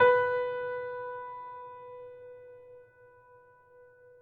piano-sounds-dev
Steinway_Grand
b3.mp3